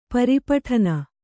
♪ pari paṭhana